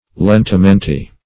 Search Result for " lentamente" : The Collaborative International Dictionary of English v.0.48: Lentamente \Len`ta*men"te\ (l[asl]n`t[.a]*m[asl]n"t[asl]; E. l[e^]n`t[.a]*m[e^]n"t[-e]), adv.